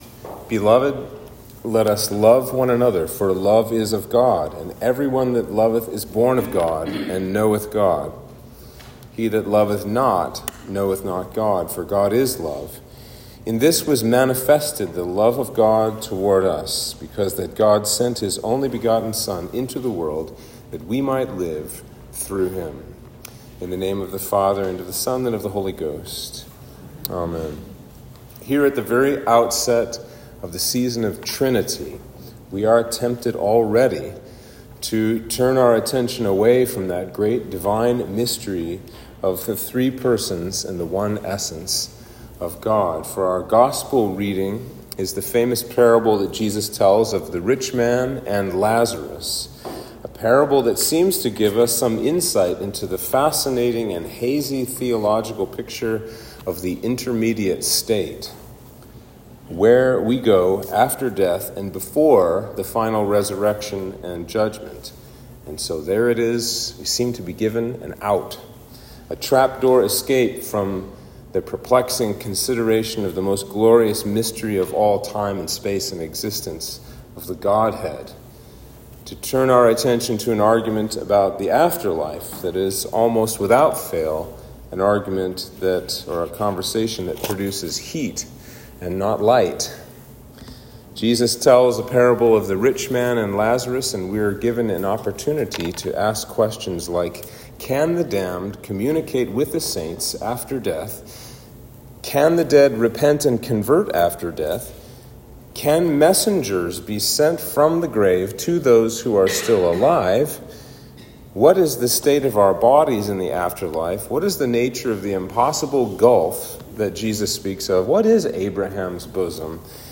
Sermon for Trinity 1